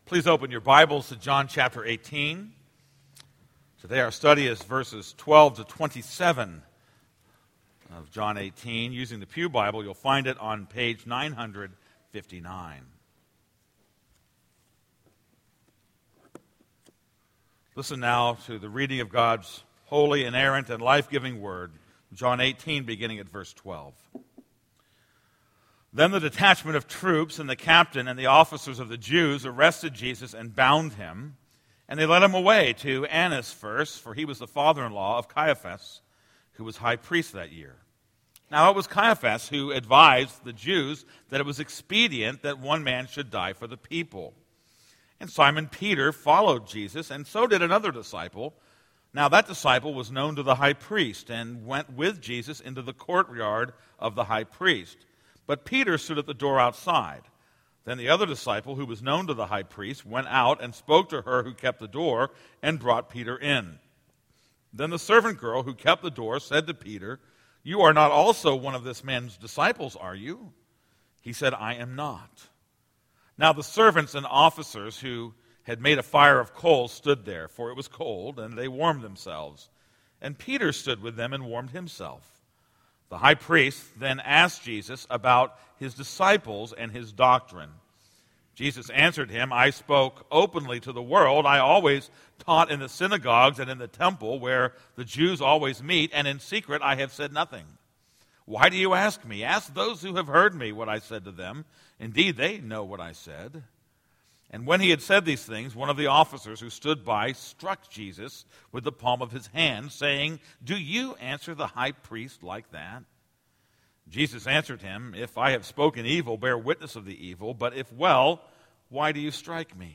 This is a sermon on John 18:12-27.